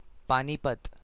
Description Hi-panipat.ogg Hindi pronunciation of the word or phrase "Panipat".
Hi-panipat.ogg